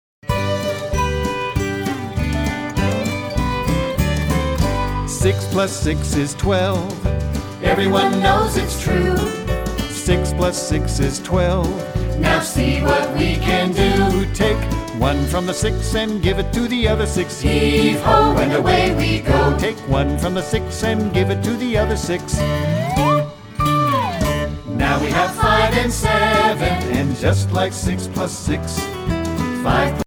- Mp3 Vocal Song Track